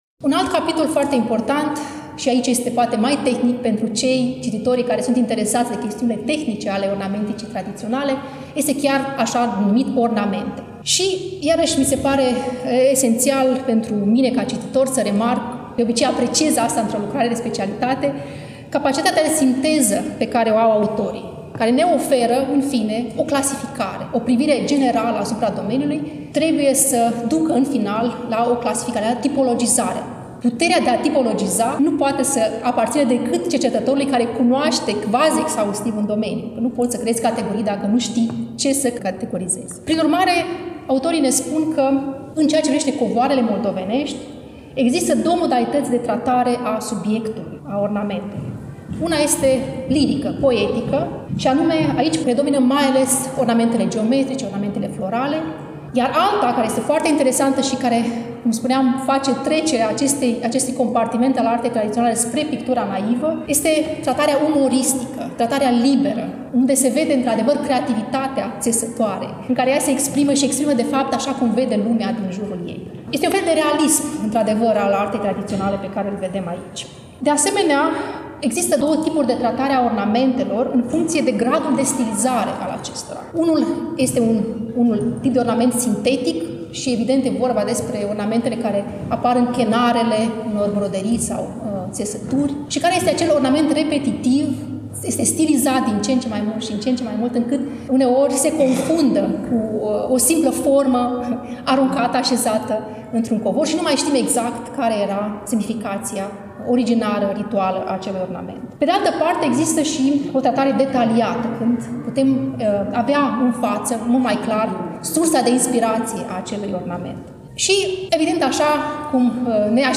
Cartea a fost lansată, la Iași, nu demult, în sala „Petru Caraman” din incinta Muzeului Etnografic al Moldovei, Palatul Culturii.